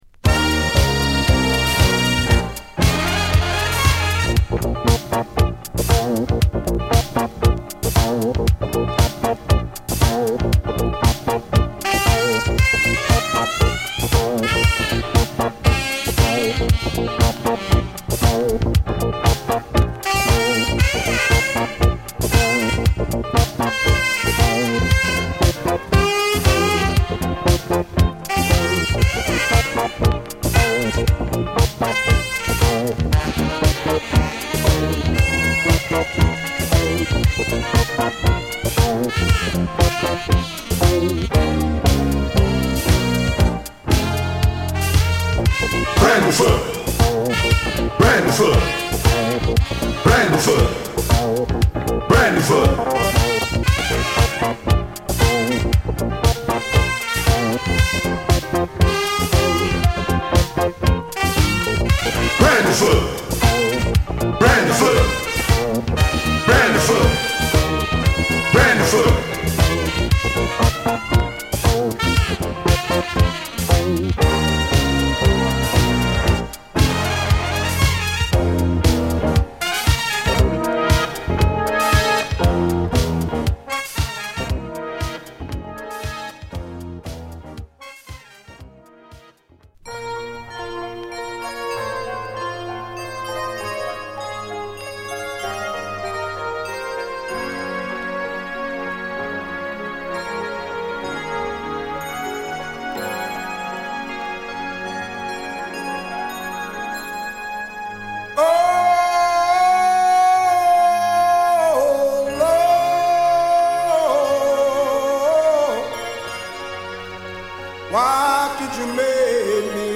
この時代らしい洗練されたシンセサウンドを多用した、ファンキーでモダンなセミ・インスト・チューン！